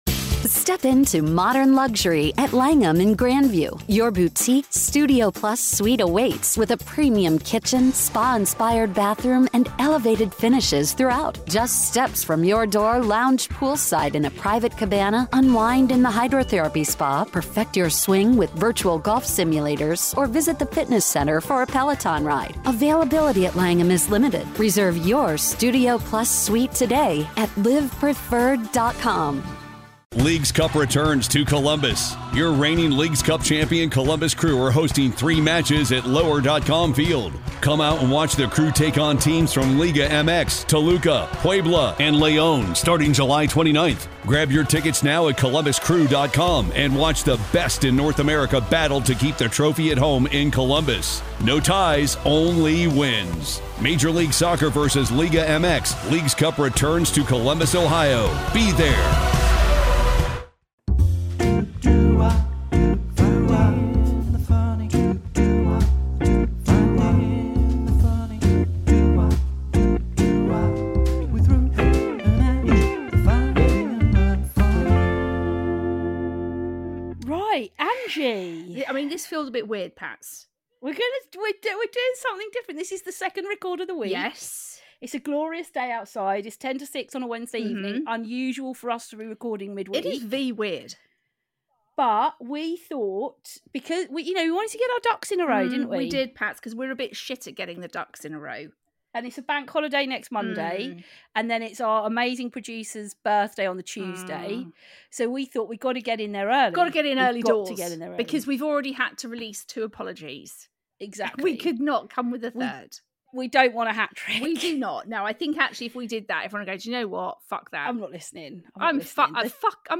The girls answer some of your questions and just have a good old chin wag. There’s talk of the Phil and Holly fall out, but this was recorded before the information came to light.